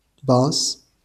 IPA/bæs/
wymowa amerykańska?/i